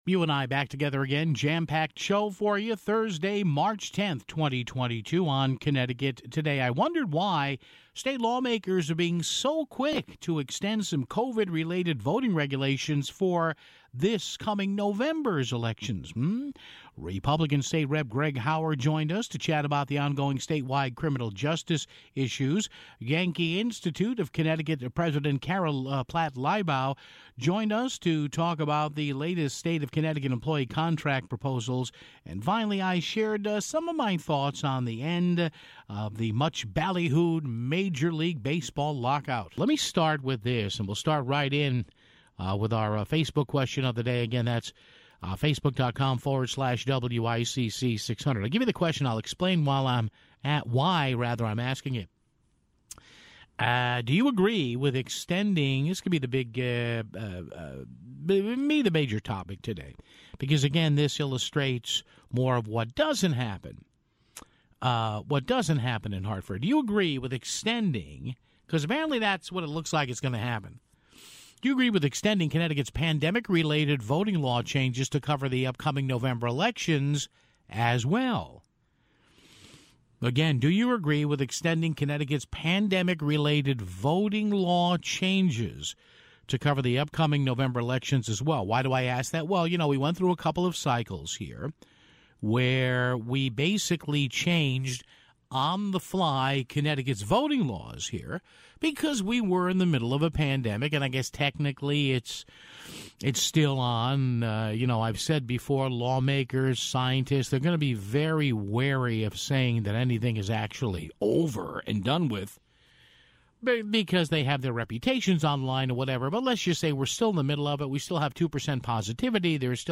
GOP State Rep. Greg Howard joined us to chat about ongoing statewide criminal justice issues (8:56).